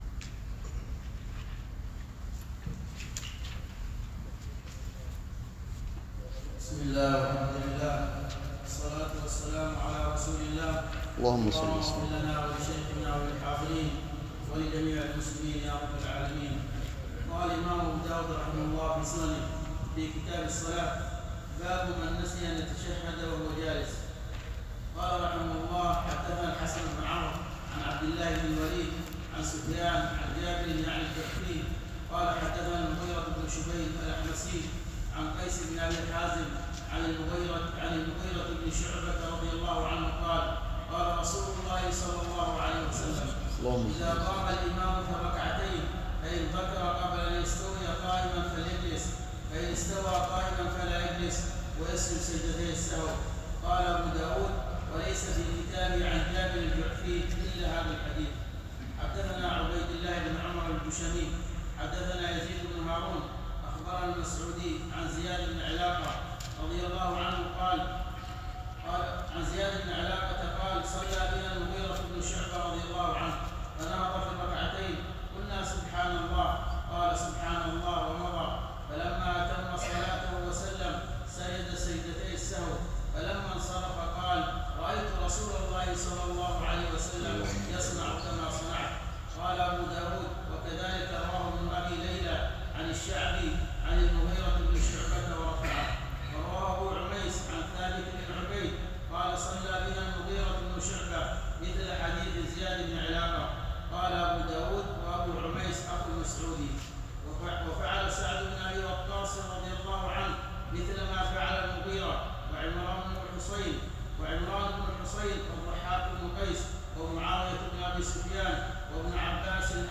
شرح كتاب الصلاة - سنن أبي داود | ١٩ شعبان ١٤٤٤ هـ _ بجامع الدرسي صبيا